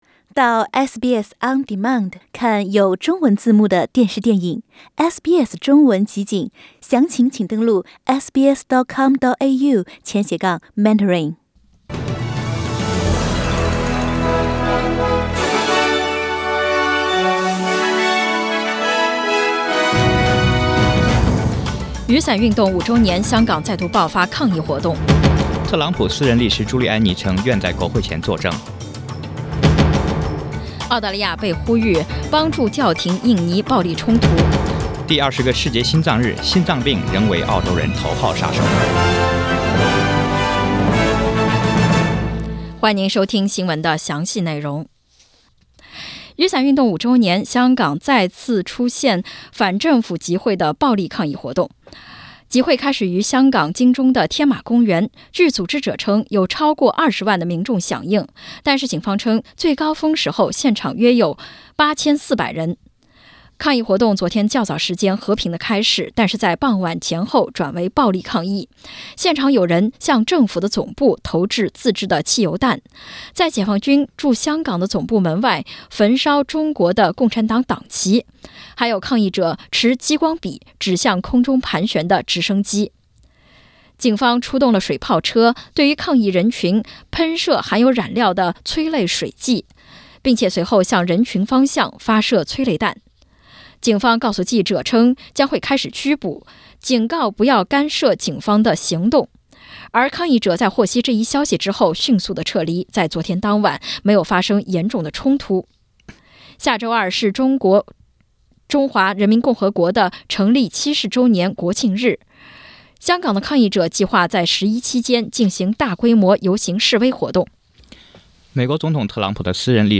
SBS 早新闻 （9月29日）